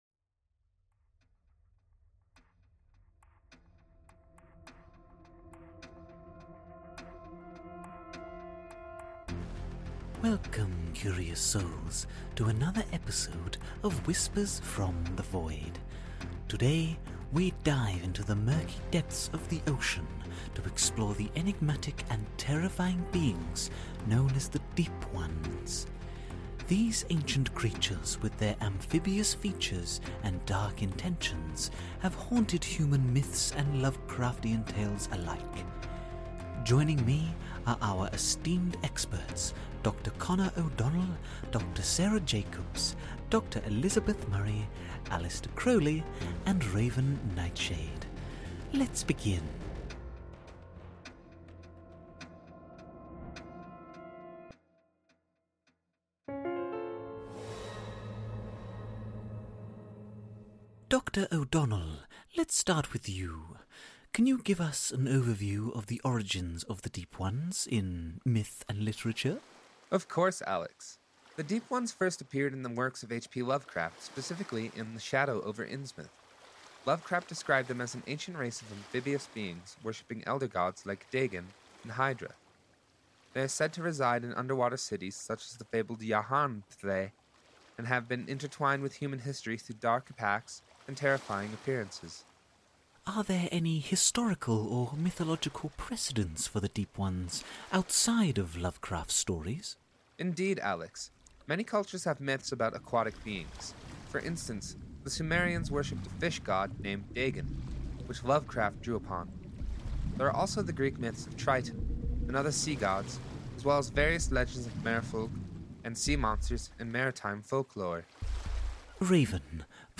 WFTV:: Deep Ones (A ROUNDTABLE DISCUSSION) by Whispers From the Void